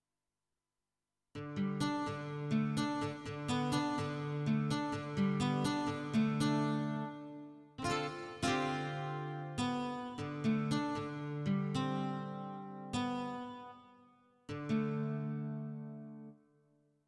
13. I SUONI - GLI STRUMENTI XG - GRUPPO "GUITAR"
08. Nylon & Steel
XG-03-08-Nylon&Steel.mp3